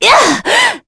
Scarlet-Vox_Attack3.wav